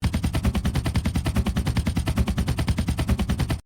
Slow Rotors
Slow Rotors.mp3